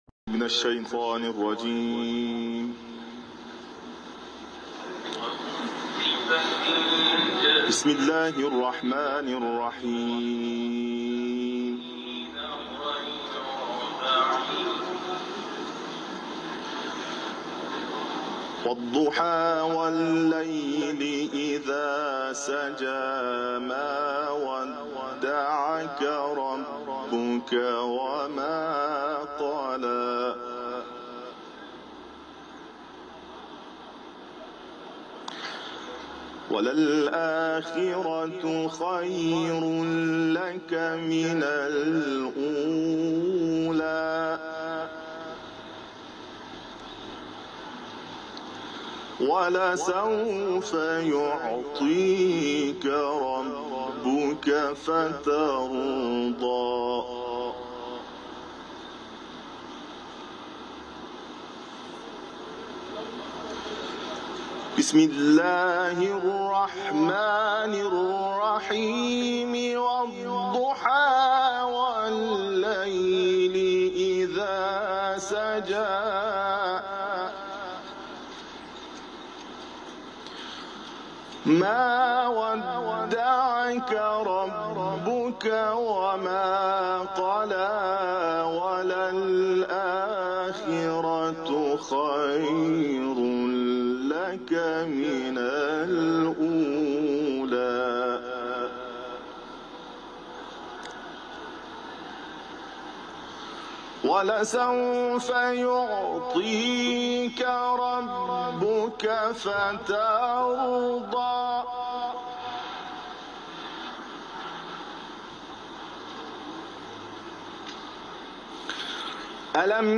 تلاوت
سوره ضحی ، حرم مطهر رضوی